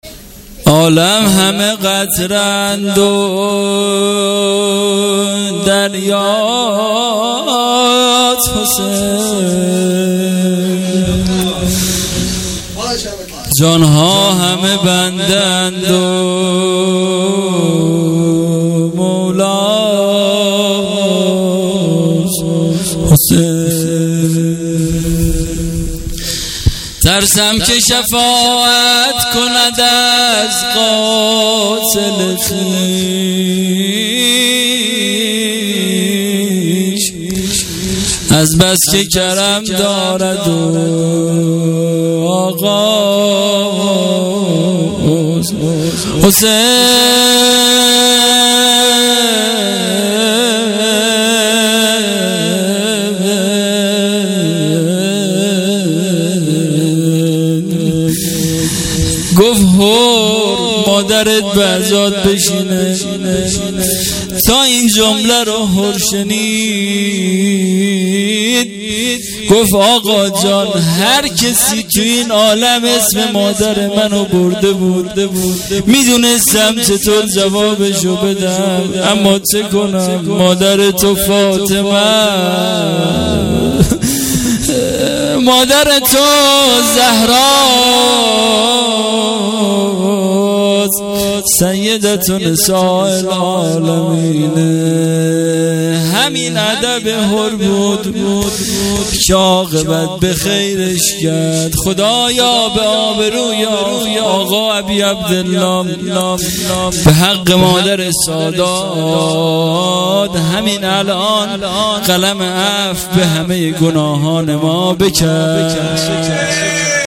شب چهارم محرم الحرام۱۳۹۸